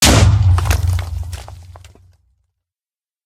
explode3.ogg